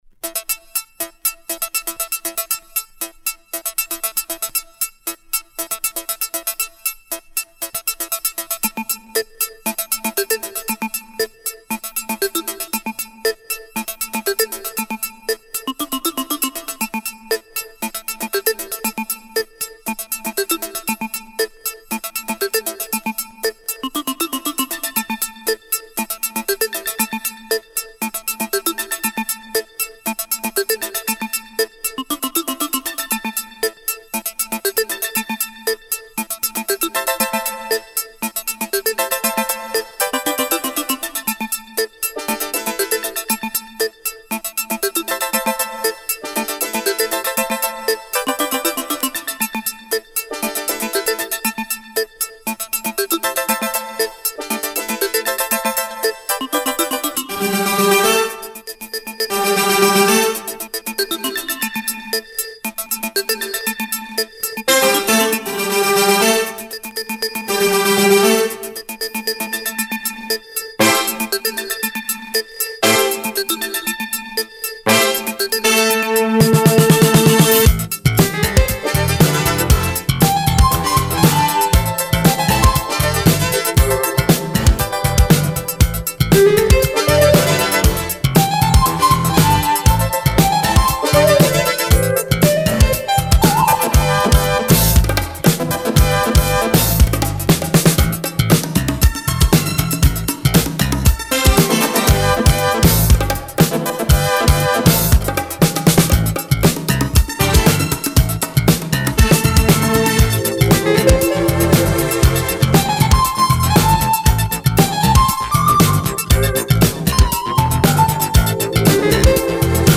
Genre: Synthpop.